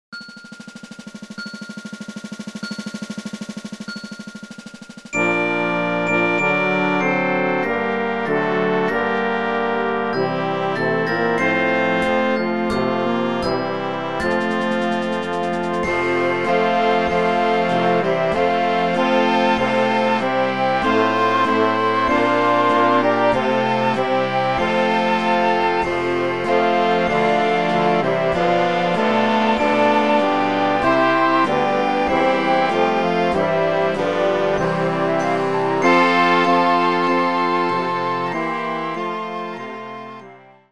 kolędy